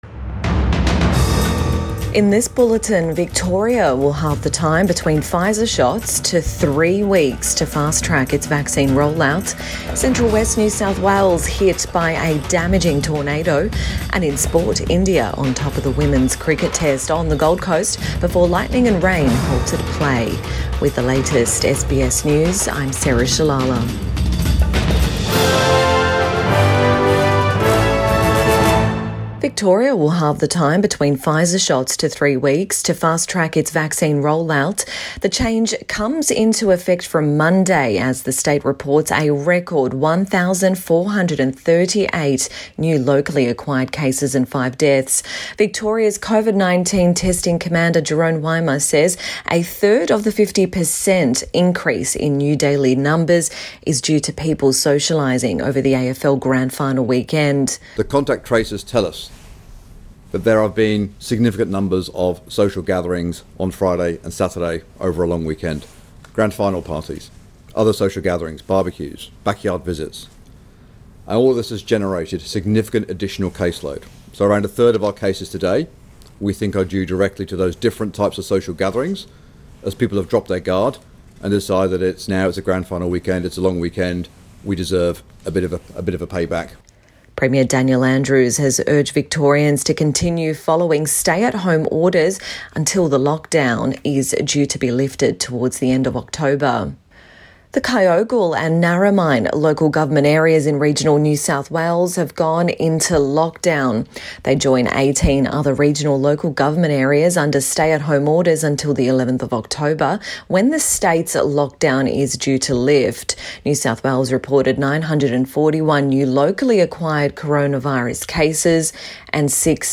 AM Bulletin 1 October 2021